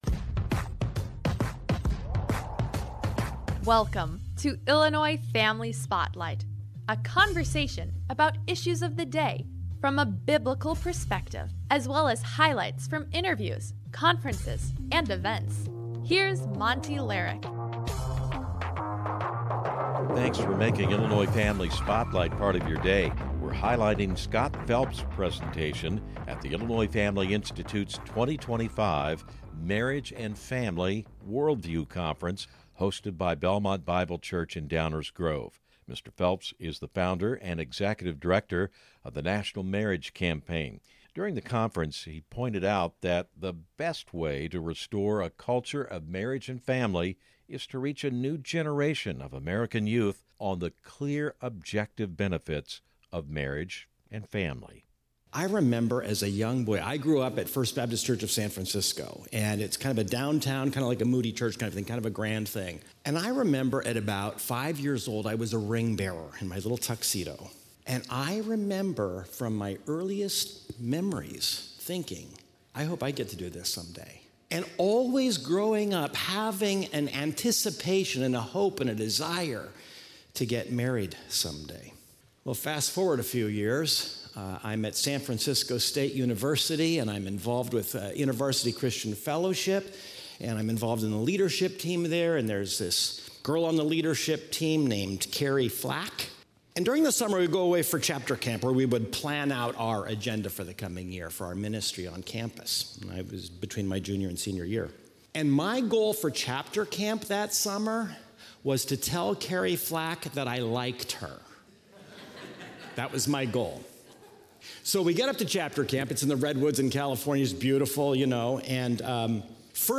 lectures given at IFI's 2023 worldview conference